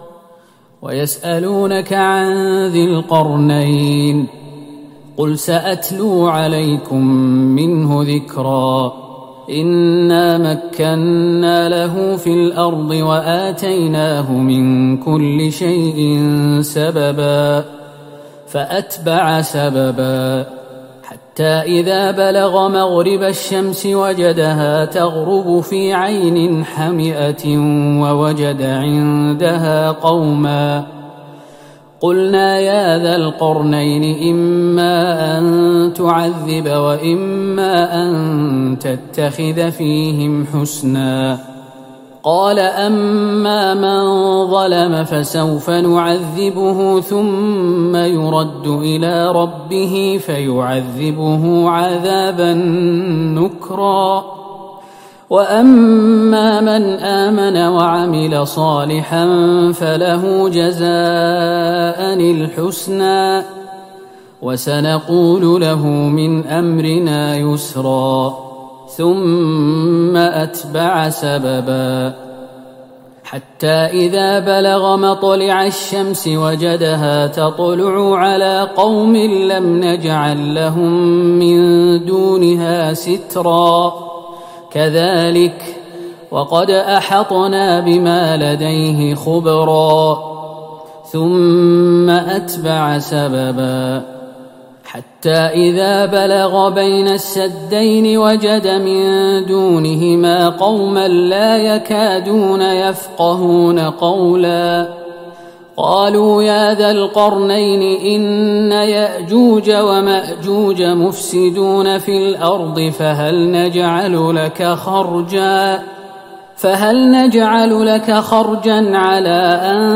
تراويح ليلة ٢١ رمضان ١٤٤١هـ من سورة الكهف { ٨٣-١١٠ } ومريم { ١-٧٦ } > تراويح الحرم النبوي عام 1441 🕌 > التراويح - تلاوات الحرمين